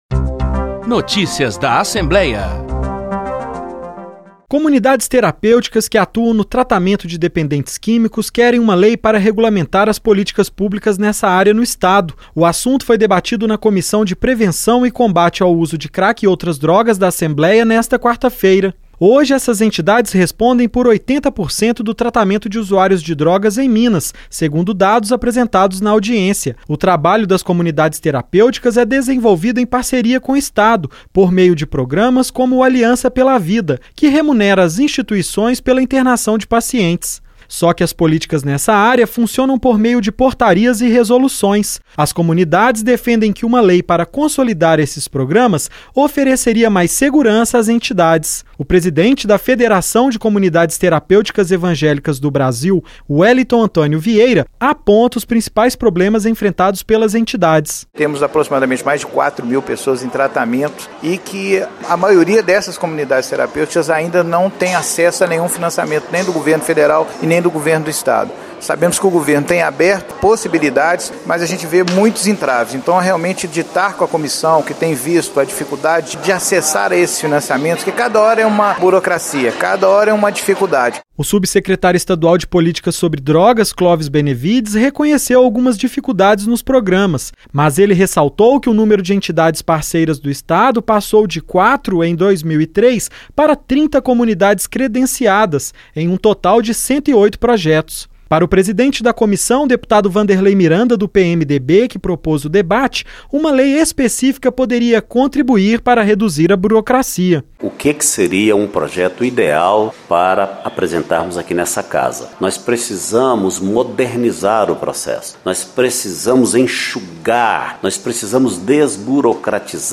Para representantes de comunidades terapêuticas, ouvidos em audiência na ALMG, uma legislação específica poderia garantir mais segurança às instituições que atuam na área.